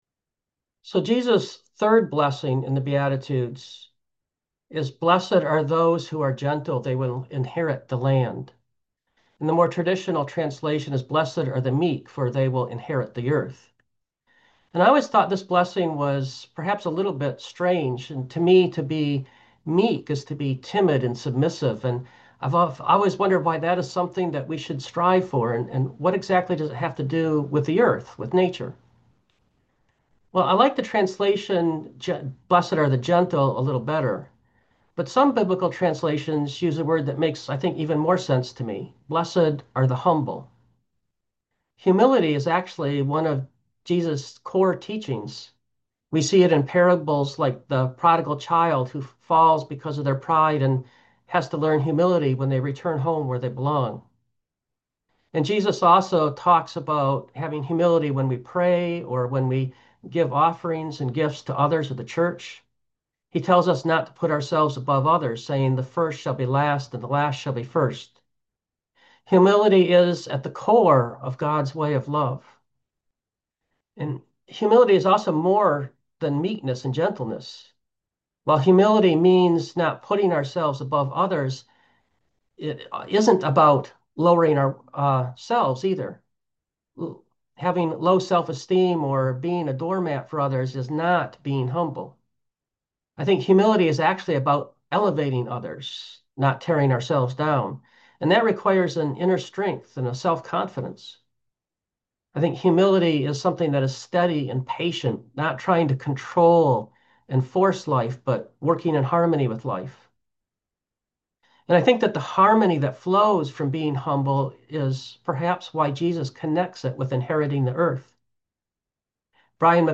Preacher: